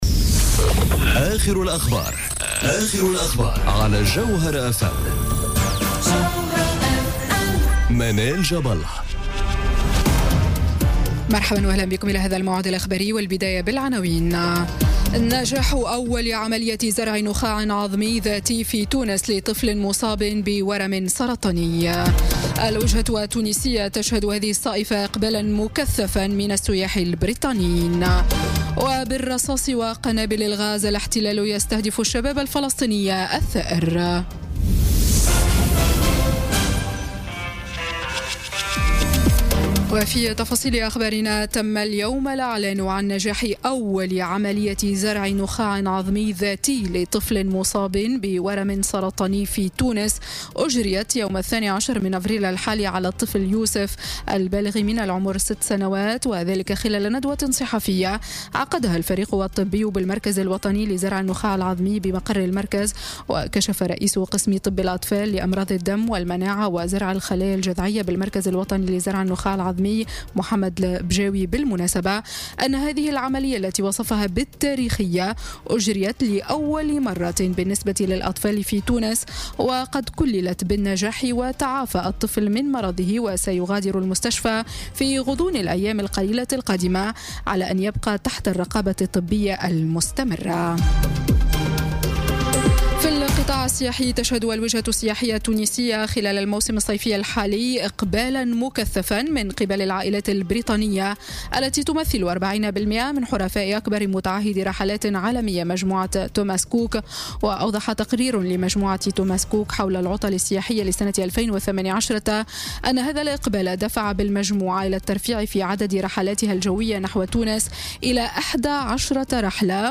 نشرة أخبار السابعة مساءً ليوم الجمعة 27 أفريل 2018